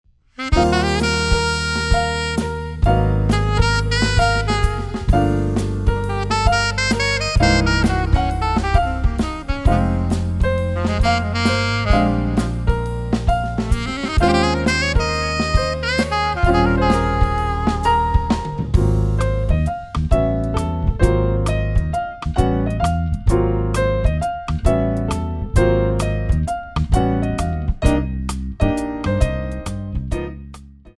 Lyrical Jazz / Modern
8 bar intro
moderato